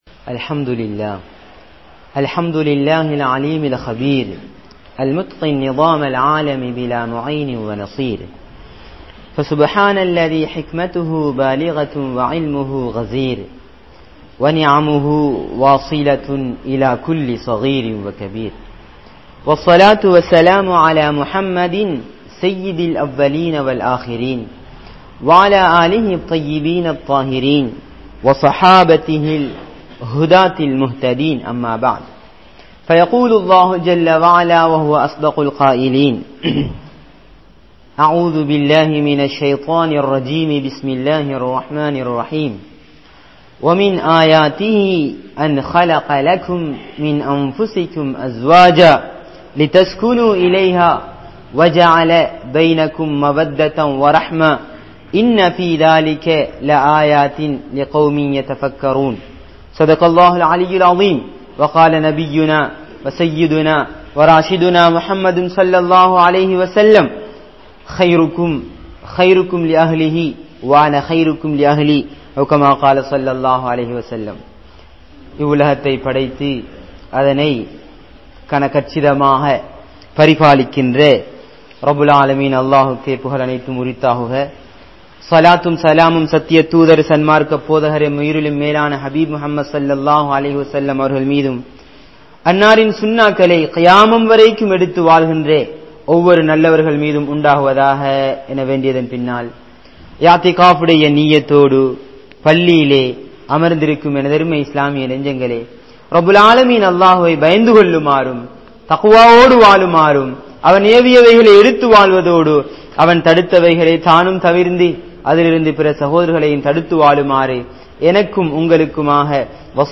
Kanavanain Kadamaihal(Duties of Husband) | Audio Bayans | All Ceylon Muslim Youth Community | Addalaichenai
Gorakana Jumuah Masjith